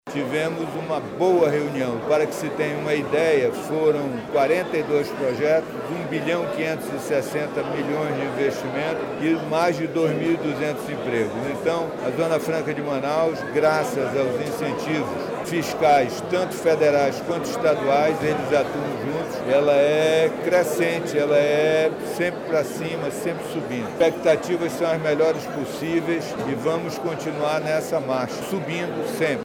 Durante o evento, foram aprovados 42 projetos industriais que totalizam R$ 1,560 bilhão, com estimativa de geração de 2,2 mil empregos, conforme destaca o secretário de Desenvolvimento, Ciência, Tecnologia e Inovação, Serafim Corrêa.